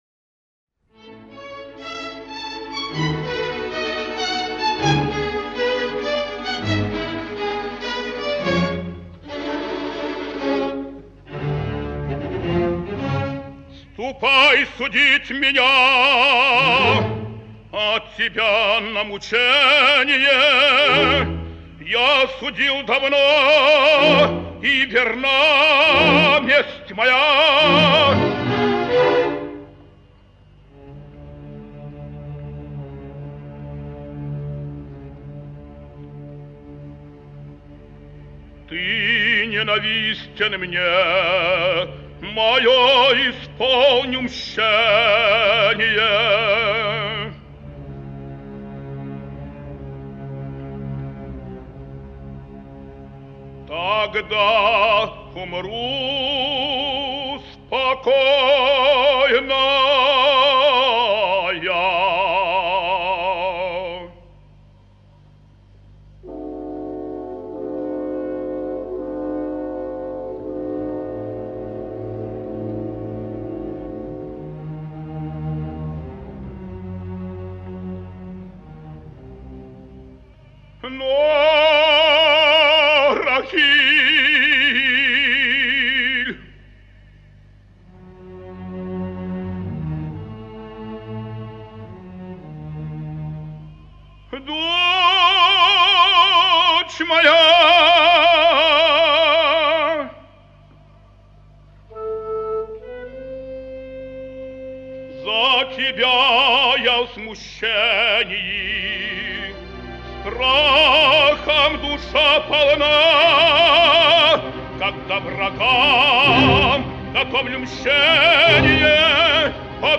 Опера «Жидовка». Речитатив и ария Элеазара. Оркестр Киевского театра оперы и балета.